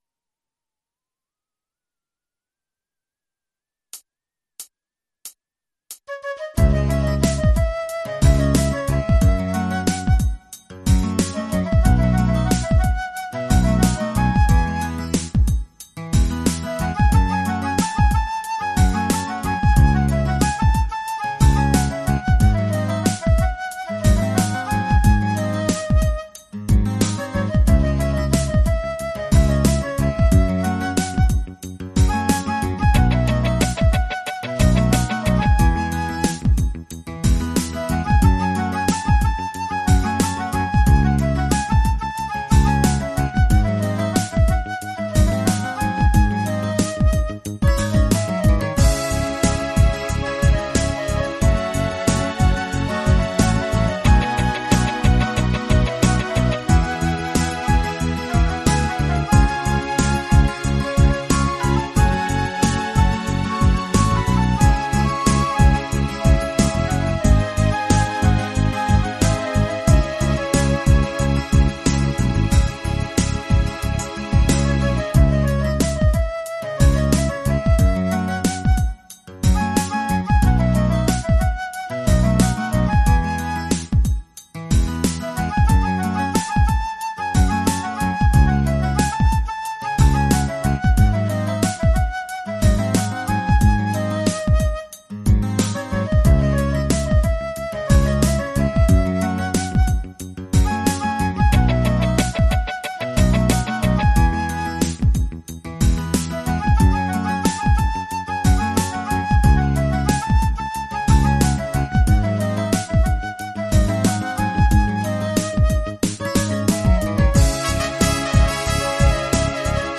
la version instrumentale multipistes